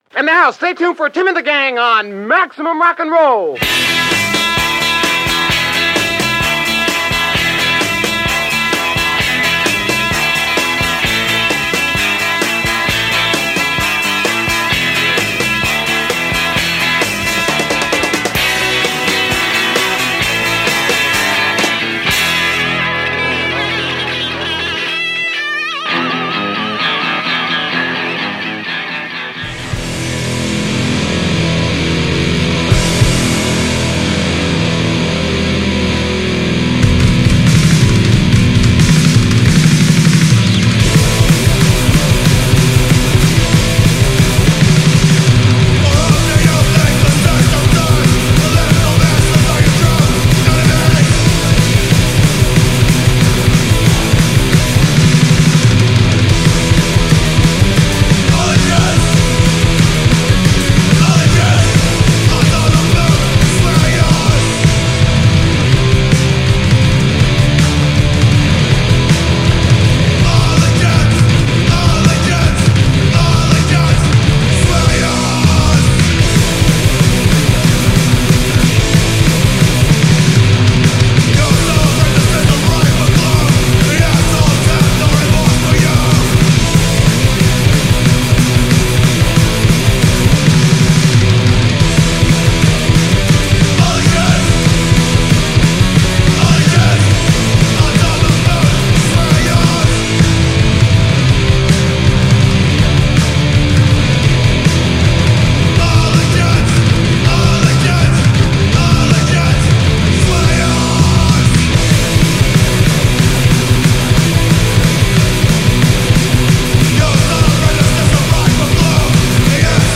selections from hardcore punk records that came out in 2025. The mrr radio show is accompanying a monthly fanzine dedicated to supporting the underground punk rock scene based in Oakland, USA.